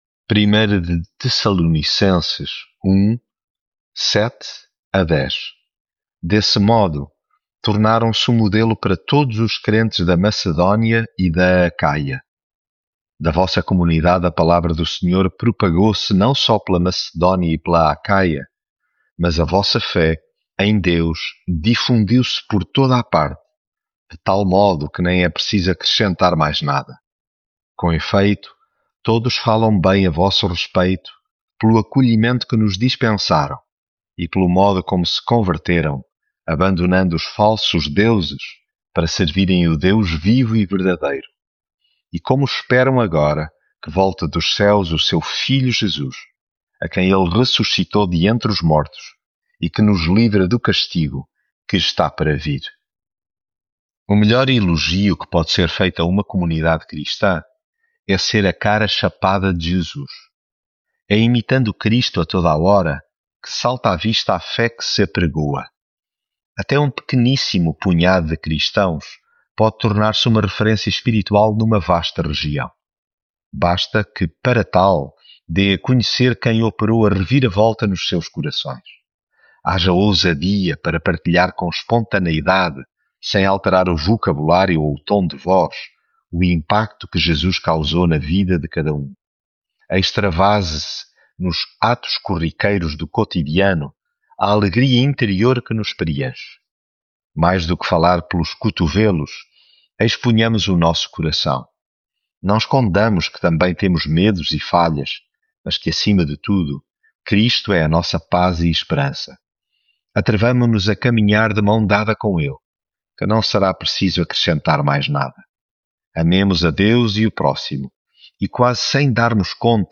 devocional 1 Tessalonicenses Desse modo, tornaram-se o modelo para todos os crentes da Macedónia e da Acaia.